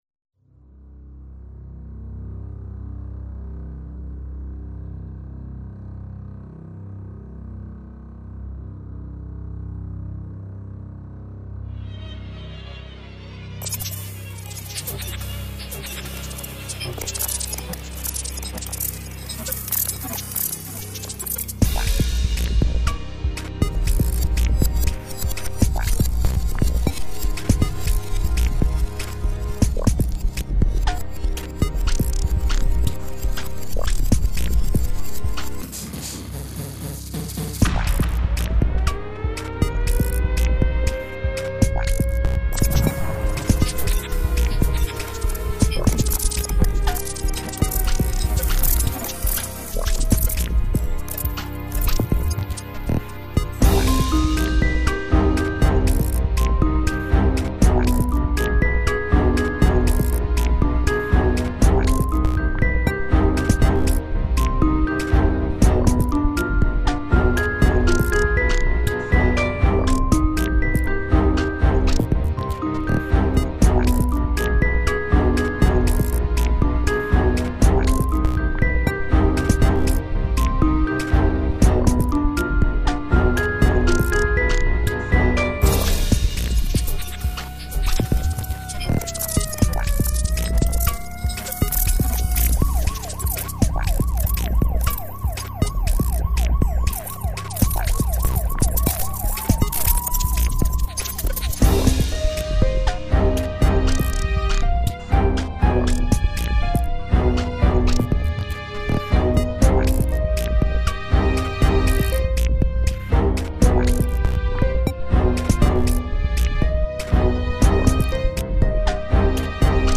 i war: I really like those effects!
But it sounds almost too much like a mixing of things instead of making music.
But those effects are really a candy to listen to. About the chord progressions, I can't say anything very good though, it's a repetition of two chords.
Theme: 13/20 not really war-like more like pop/R&B